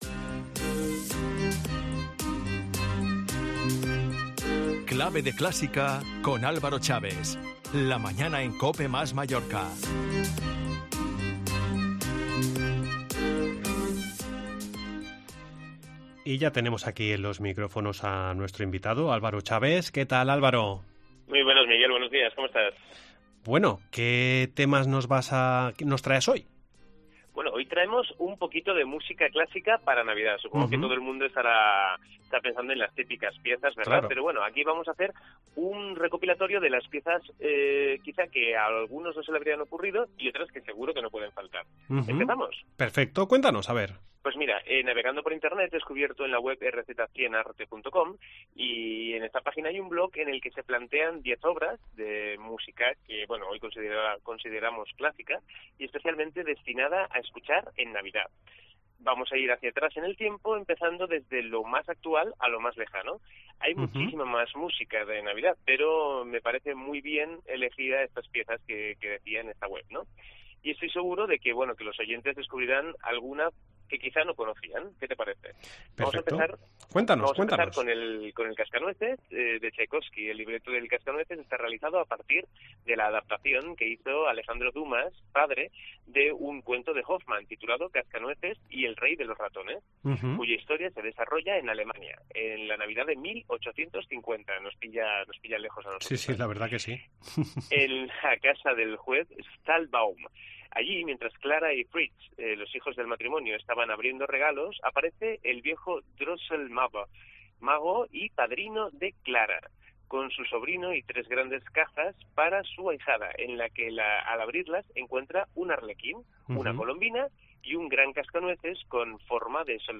AUDIO: El tenor lírico y divulgador de música clásica nos acerca de una manera diferente esta música tan especial